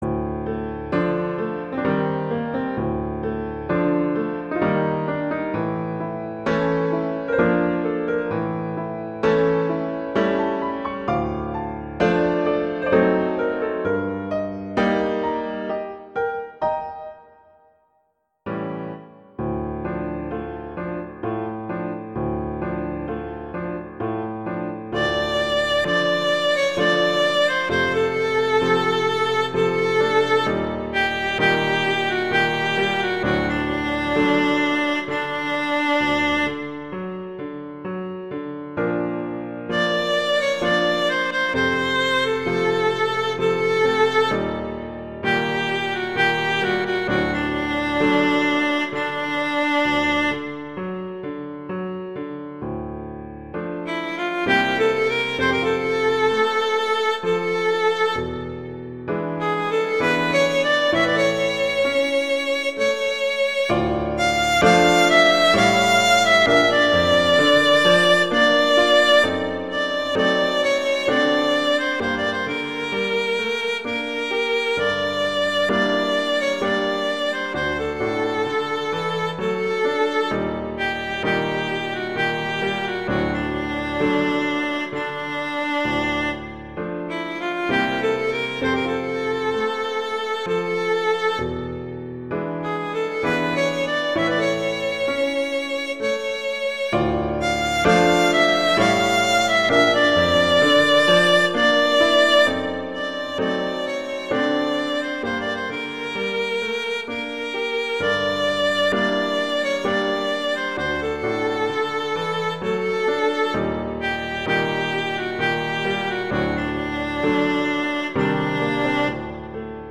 Instrumentation: violin & piano
classical, world, children